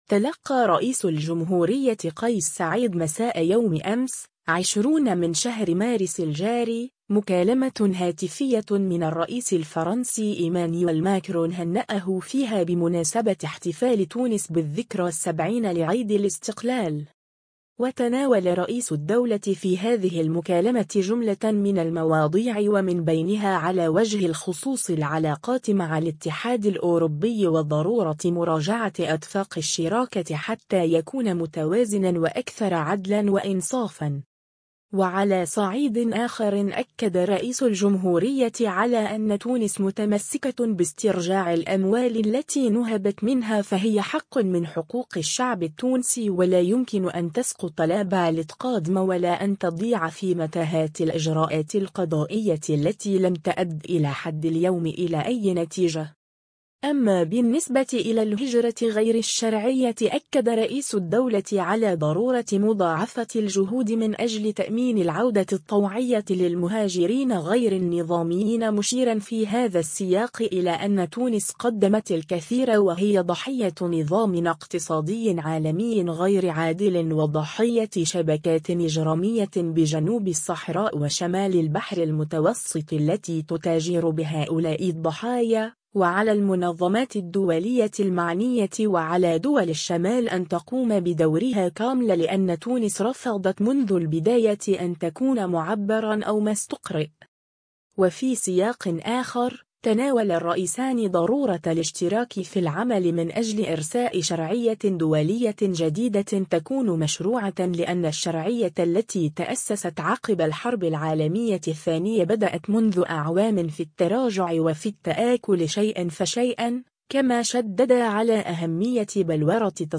مكالمة هاتفية بين رئيس الجمهورية قيس سعيد و الرئيس الفرنسي إيمانويل ماكرون